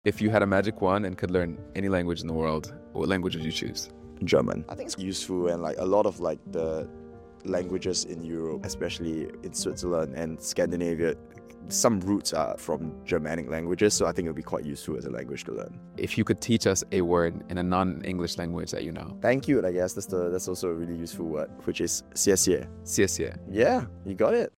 I got to visit Oxford to ask the students about how many languages they speak and hear on campus.